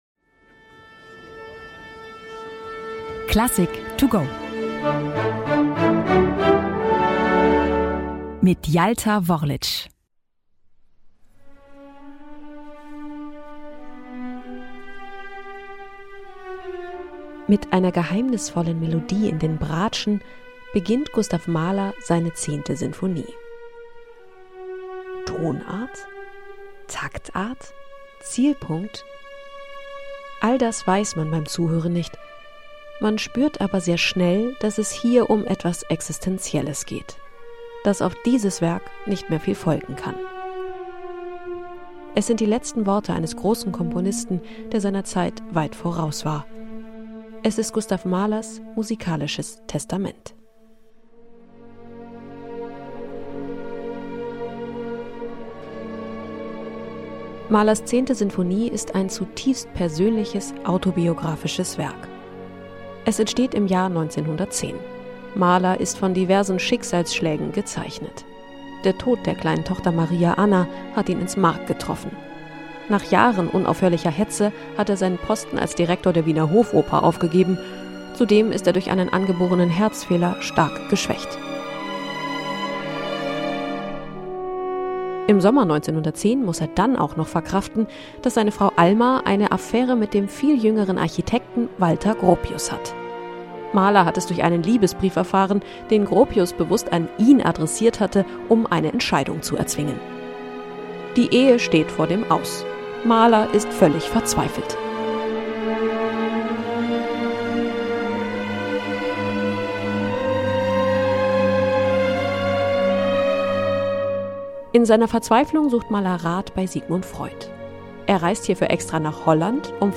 Kurzeinführung